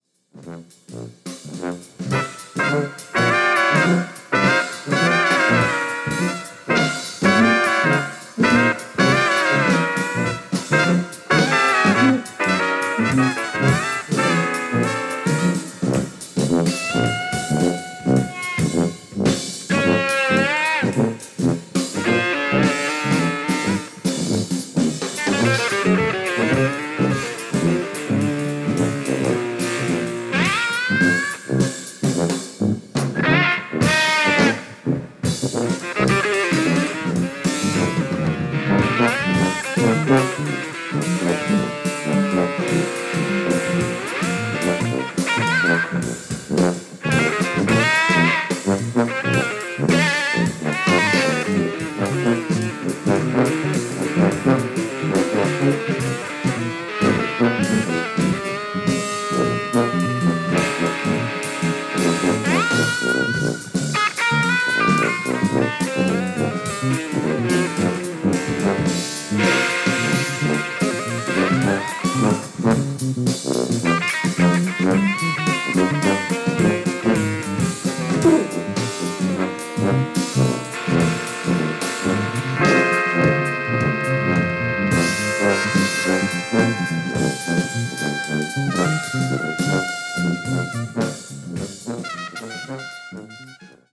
Drums
Tuba
Electric Bass
Trumpet, Cornet, Flugelhorn
Guitar, Synthesizer